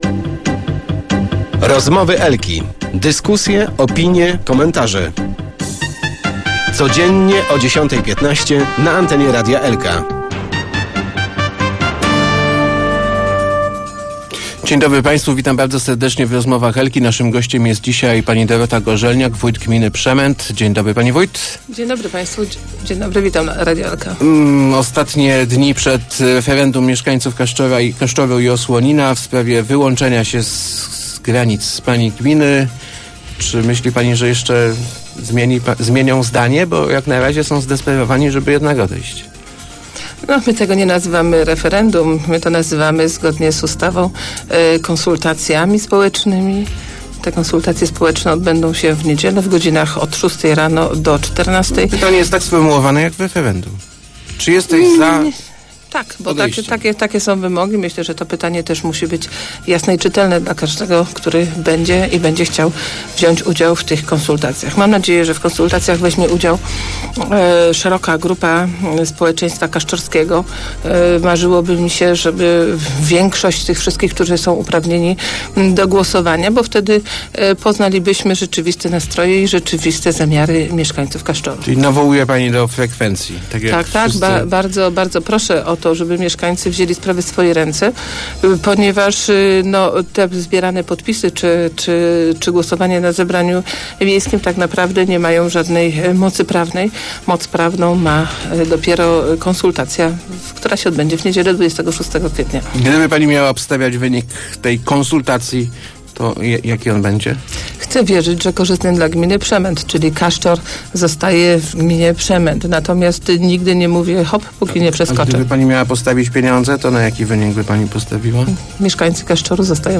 W najbli�sz� niedziel� w Kaszczorze i Os�oninie odb�d� si� konsultacje spo�eczne w sprawie zmian granic gminy Przem�t, czyli przej�cia wsi do gminy Wijewo. Jestem przekonana, �e mieszka�cy opowiedz� si� za pozostaniem u nas – mówi�a w Rozmowach Elki wójt Przem�tu Dorota Gorzelniak.